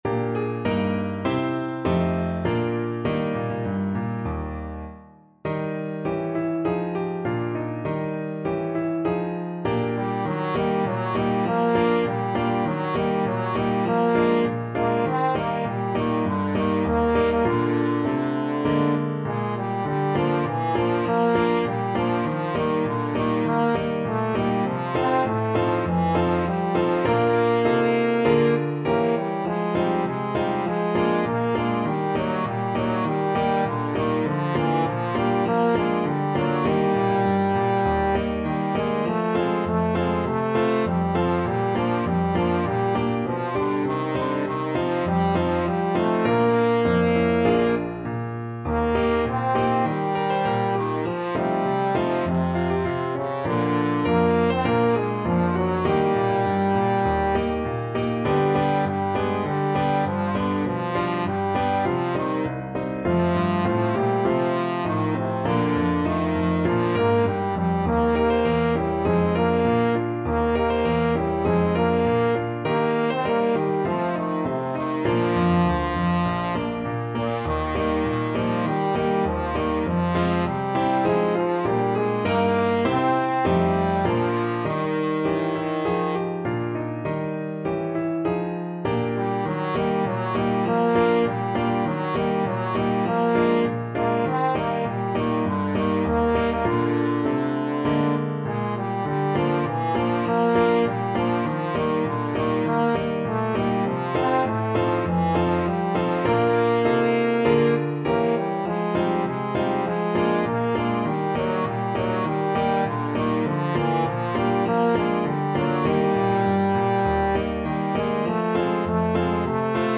2/2 (View more 2/2 Music)
Bb3-C5
Moderato = c.100